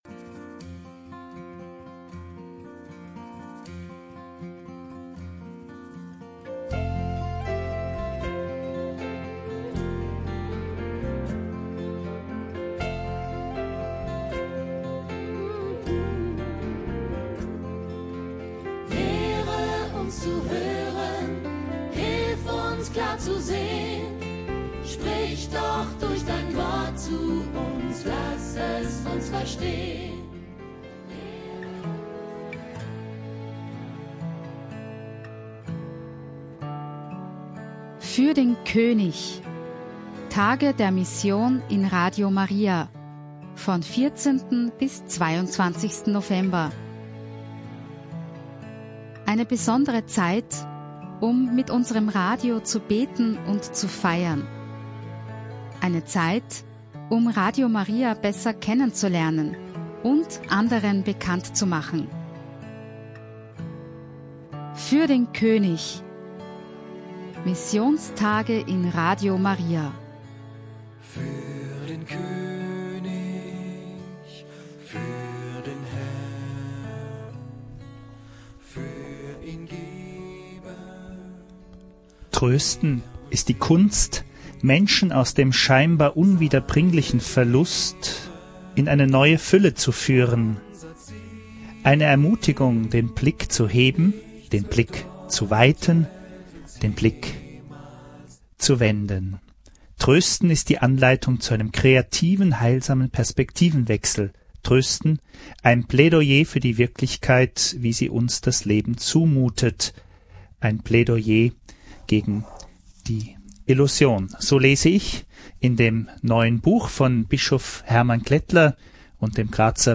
Novemeber ein Interview mit Bischof Hermann Glettler im Studio Innsbruck durch – die Sendung „Wort des Lebens“ zum Nachhören.